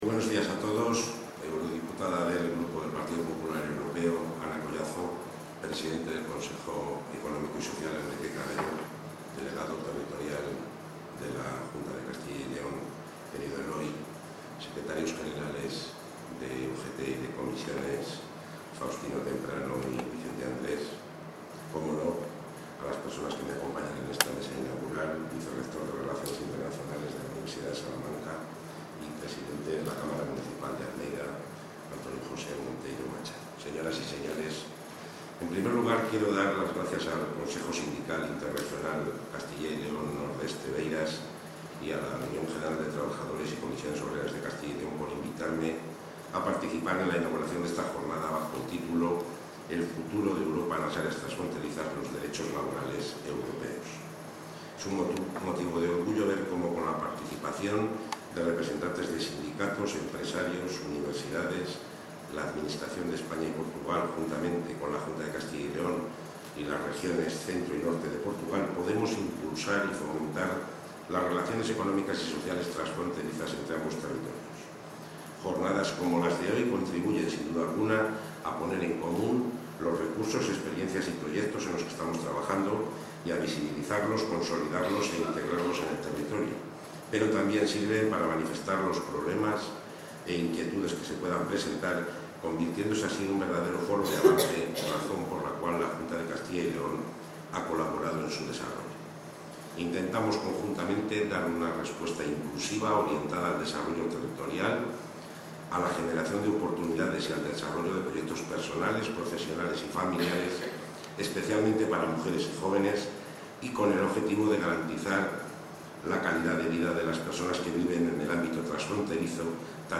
Intervención del consejero de la Presidencia.
González Gago ha inaugurado la jornada ‘El futuro de Europa en las áreas transfronterizas. Los derechos laborales europeos’, organizada por el Consejo Sindical Interregional, órgano que se encarga de analizar las condiciones de trabajo y movilidad de los trabajadores a uno y otro lado de la frontera entre España y Portugal, sobre todo en los casos en los que existe una movilidad entre ambos países.